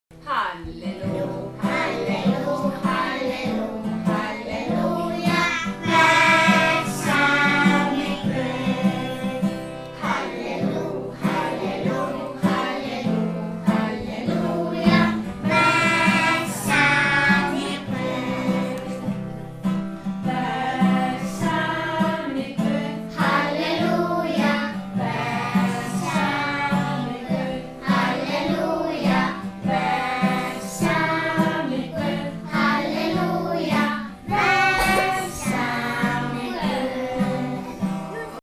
(Víxlsöngur: Einn hópur syngur „Hallelúja“, annar hópur syngur „vegsamið Guð“) Texti: Höfundur ókunnur – Jón Hjörleifur Jónsson Lag: Höfundur ókunnur